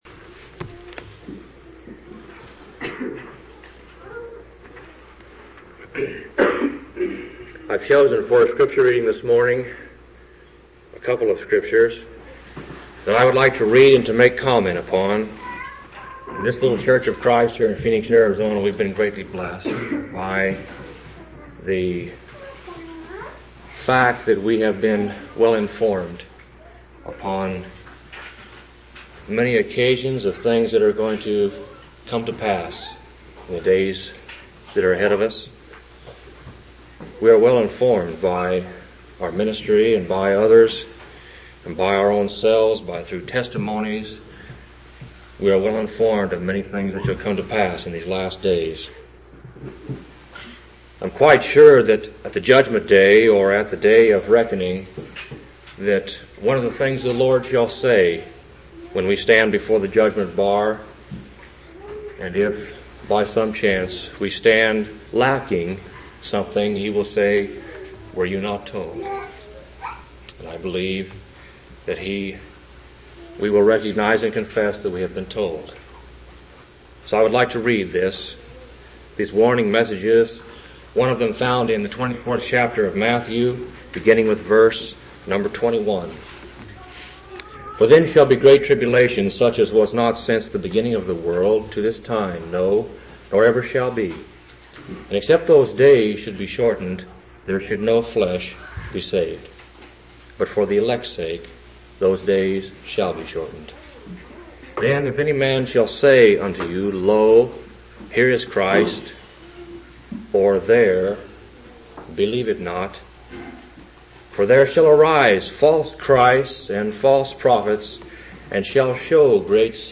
12/30/1984 Location: Phoenix Local Event